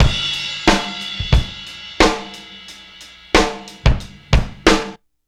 Lemoncrash 91bpm.wav